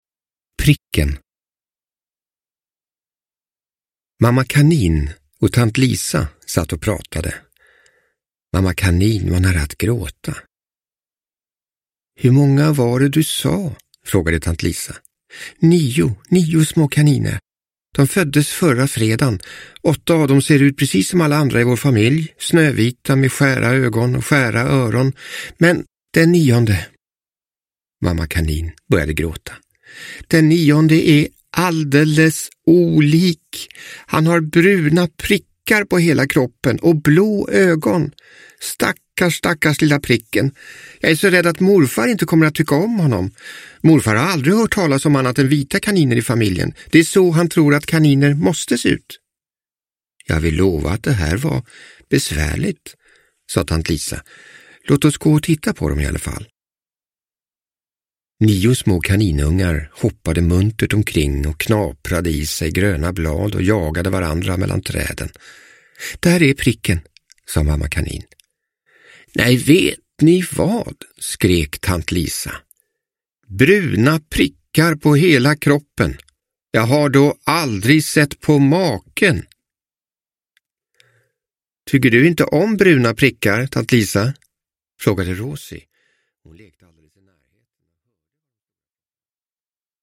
Pricken – Ljudbok – Laddas ner